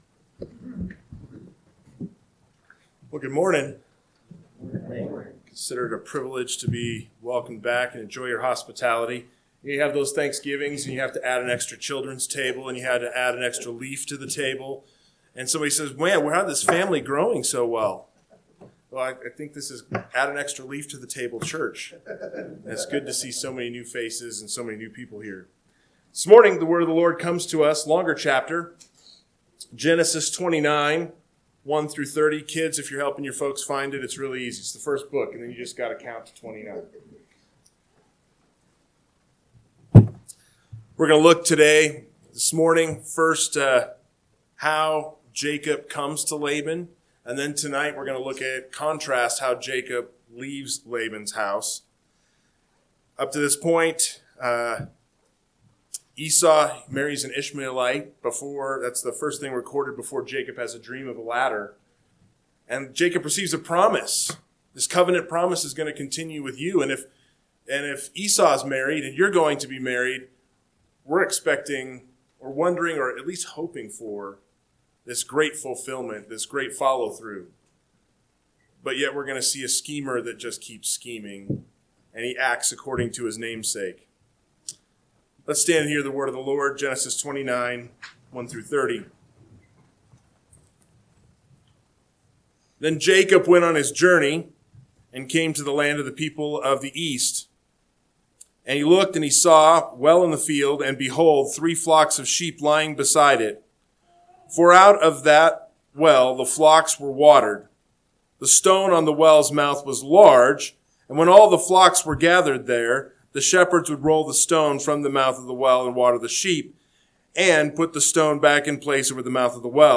AM Sermon – 11/30/2025 – Genesis 29:1-30 – Northwoods Sermons